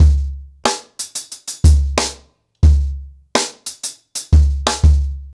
CornerBoy-90BPM.25.wav